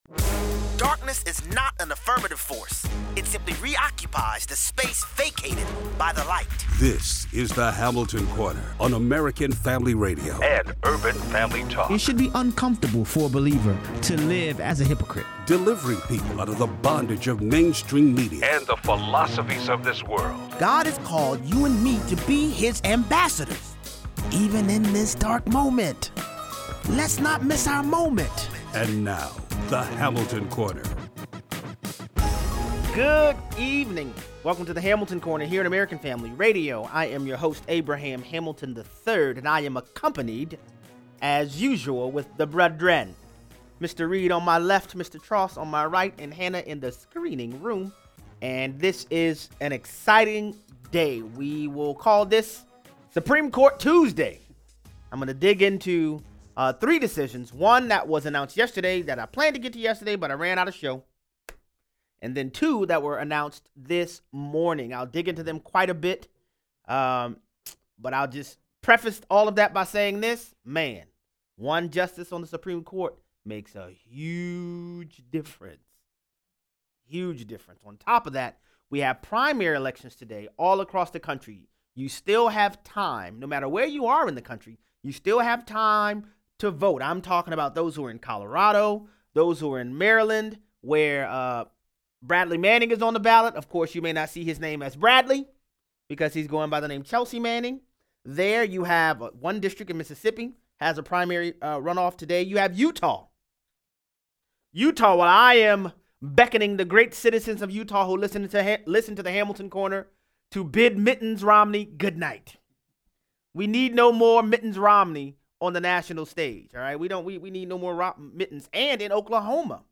That’s what the Supreme Court said as they rebuked the state of California. Callers weigh in.